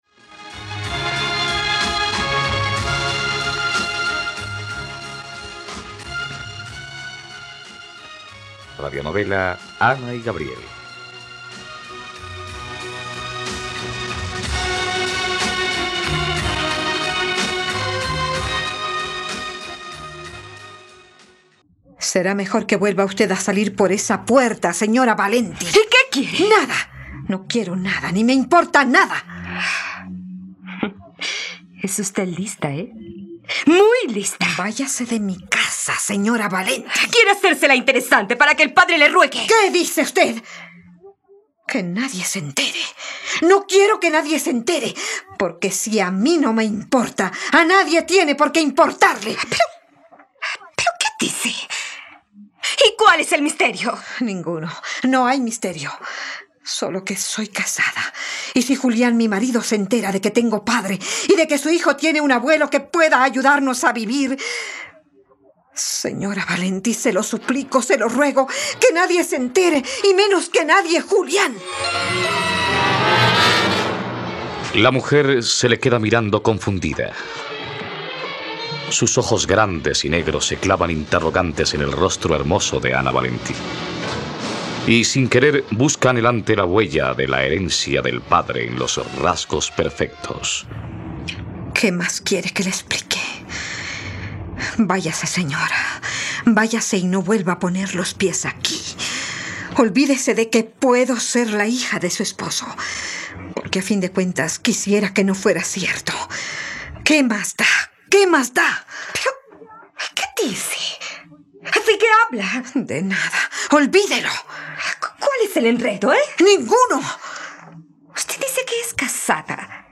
Ana y Gabriel - Radionovela, capítulo 54 | RTVCPlay